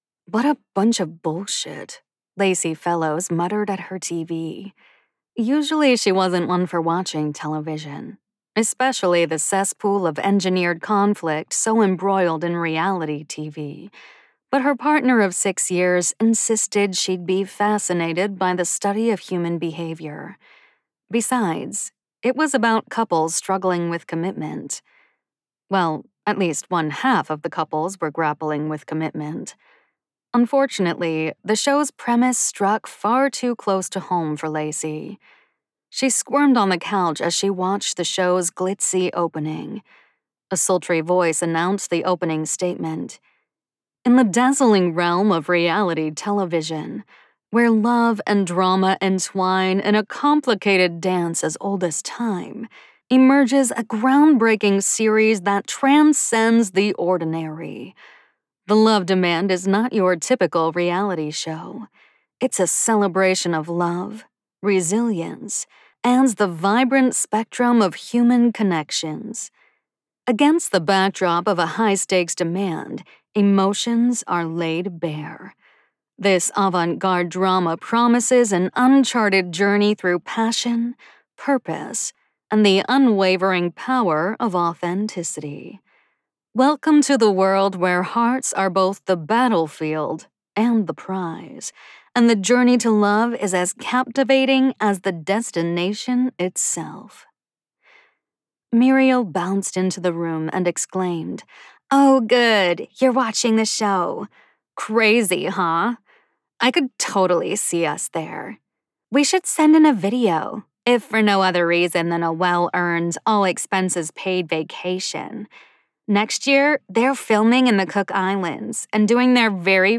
The Love Demand by Annette Mori, [Audiobook]